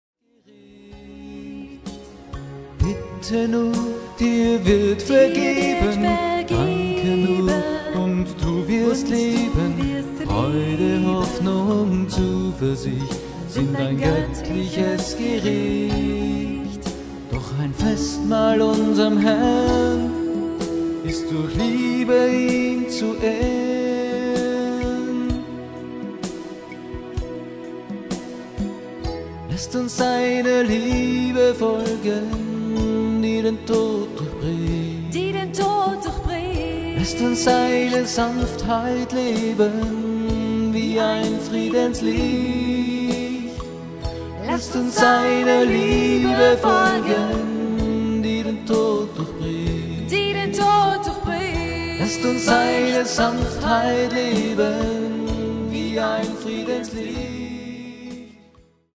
Lobpreis & Anbetung